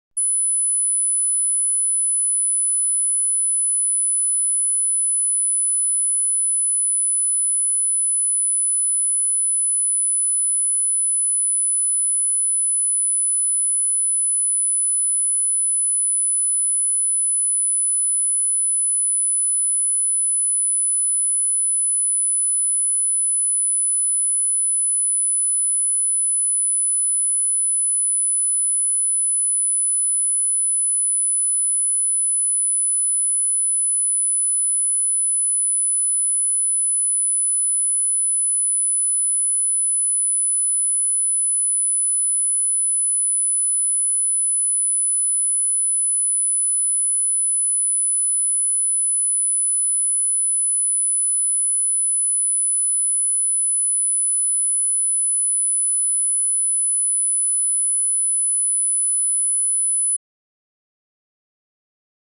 Left Sinus of 10KHz _ 10dB - Test Tones.mp3